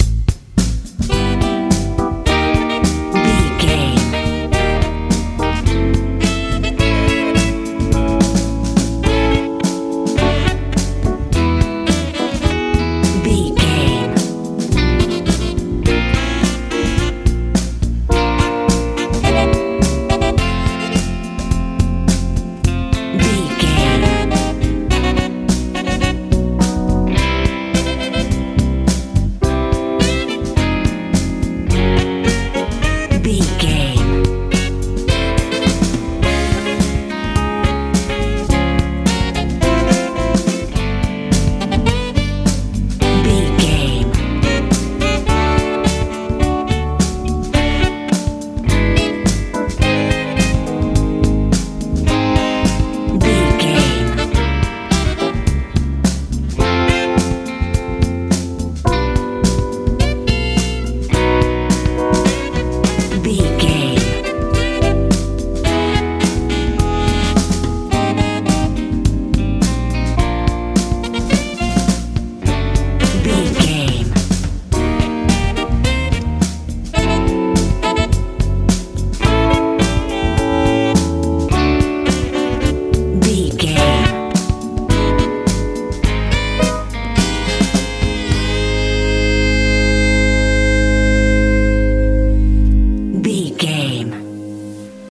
funk
Ionian/Major
B♭
groovy
funky
electric guitar
horns
bass guitar
drums
piano
70s
80s